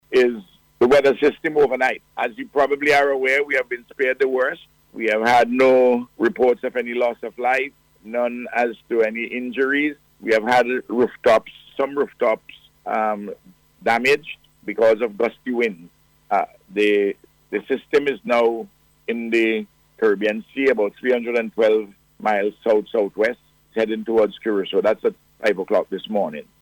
Speaking on NBC Radio’s Face to Face program this morning the Prime Minister however said some house roofs were damaged by high winds